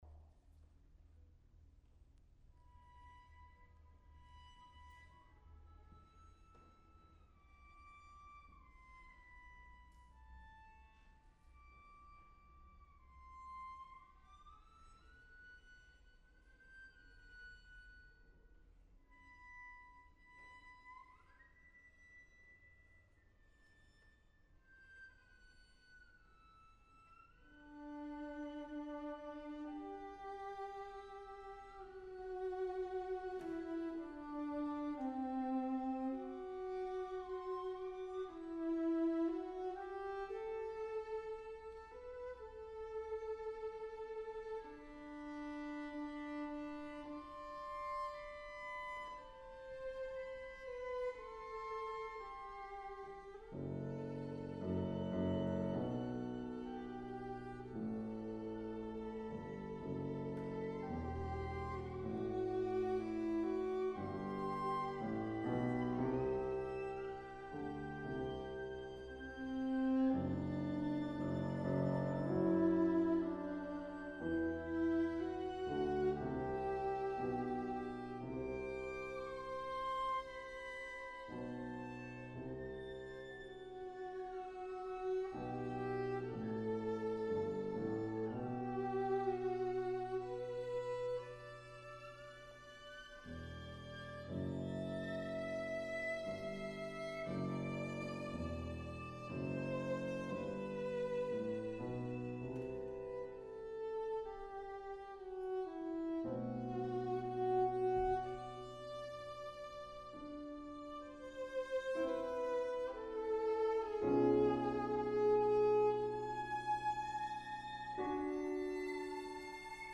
Piano Trio No.2 in E minor Op.67 - West Cork Music
Venue: St. Brendan’s Church
Instrumentation: vn, vc, pf Instrumentation Category:Piano Trio
[violin]
[cello]
[piano]